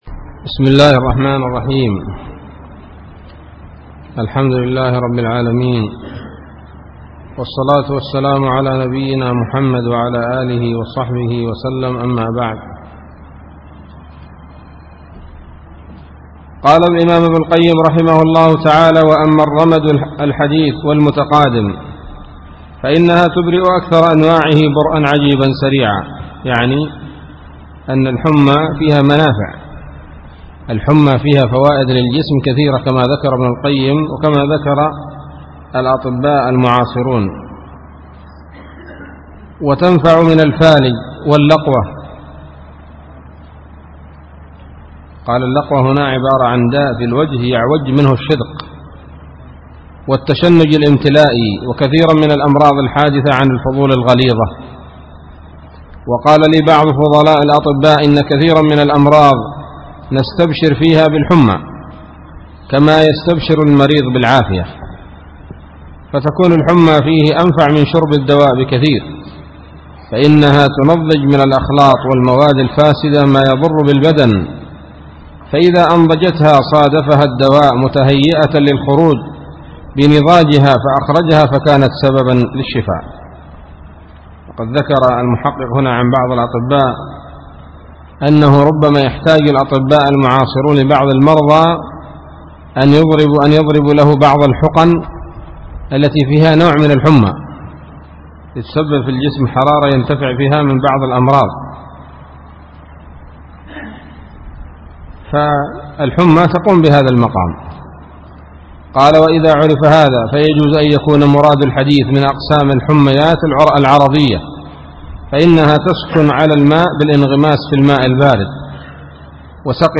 الدرس السابع من كتاب الطب النبوي لابن القيم